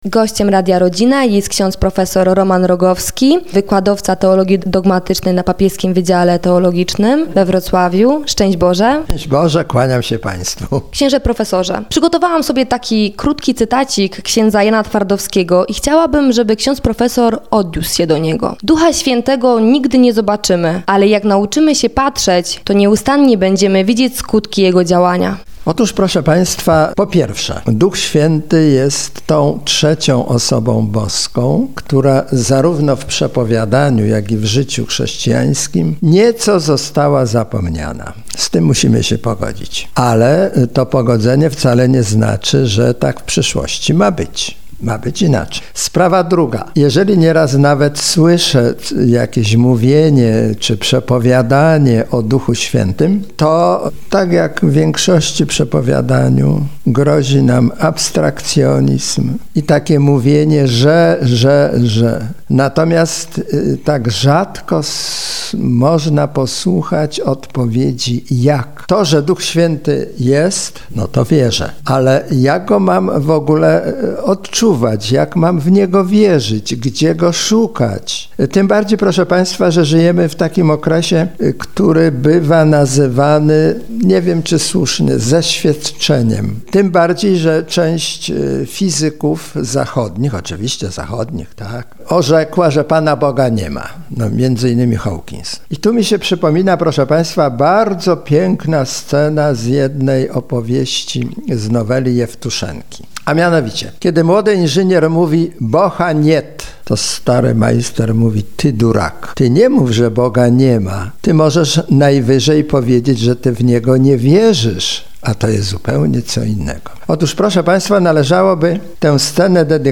Udzielał wywiadów również w Katolickim Radiu Rodzina.